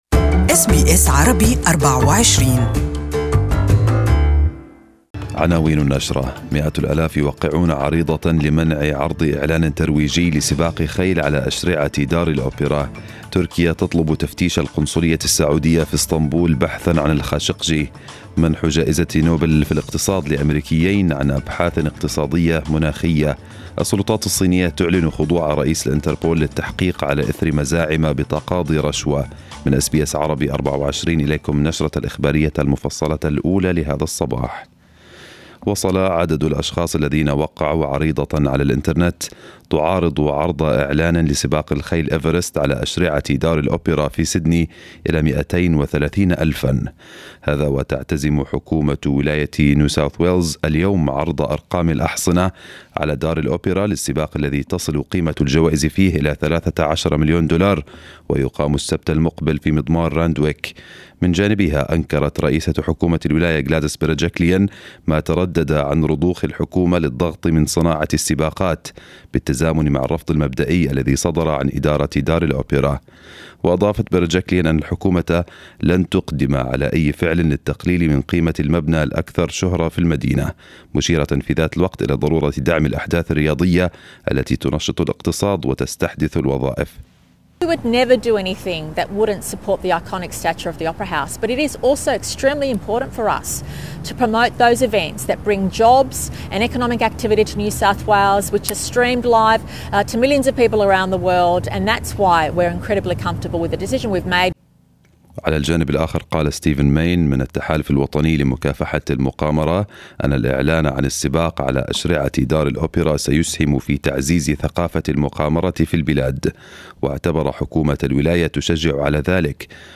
Detailed news bulletin of the morning